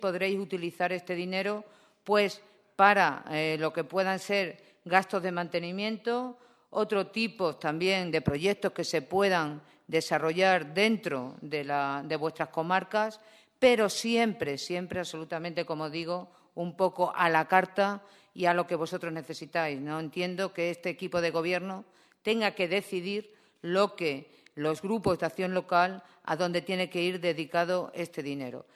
CORTES DE VOZ
06/04/2016.- Este miércoles se ha llevado a cabo la firma de los convenios entre la Diputación de Cáceres y los 14 Grupos de Acción local (GAL) de la provincia, además de con la Red Extremeña para el Desarrollo Rural (REDEX), dando así cumplimiento a lo aprobado en la última sesión plenaria.